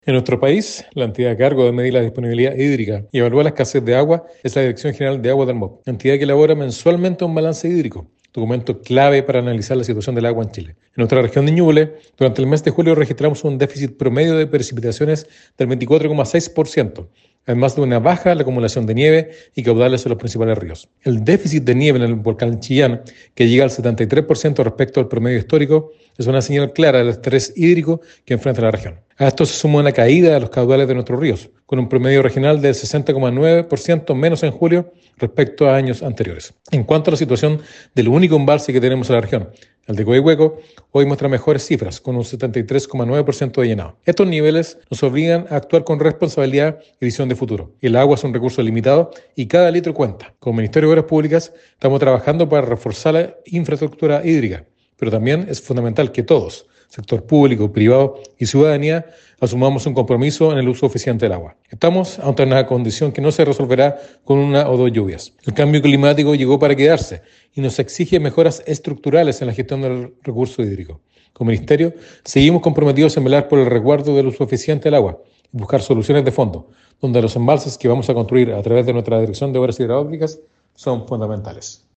El Seremi del MOP, Freddy Jelves, llamó a reforzar el uso eficiente del agua y a trabajar unidos por una gestión hídrica sostenible.
Freddy-Jelves-seremi-MOP-2.mp3